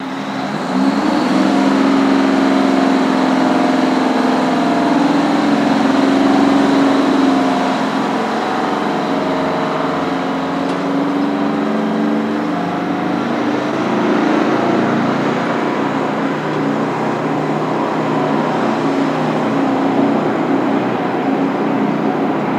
Highwayman Truck Accelerate Out